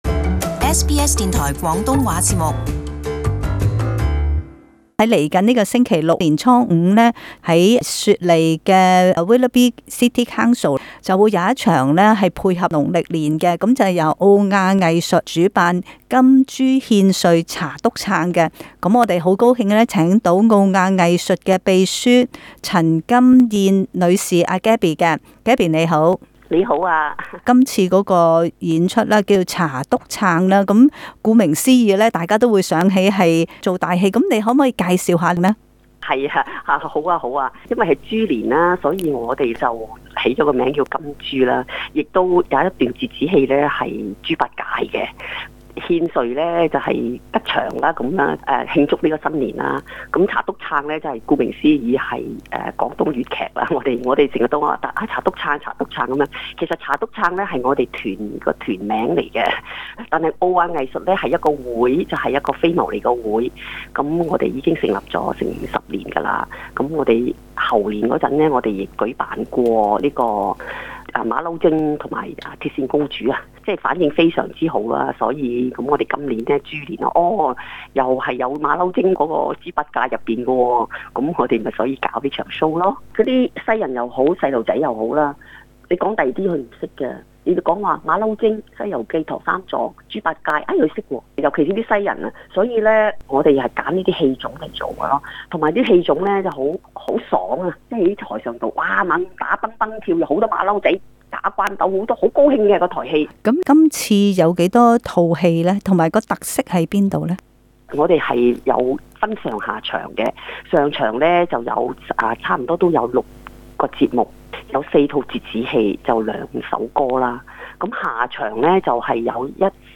【社區專訪】金豬獻瑞查篤撑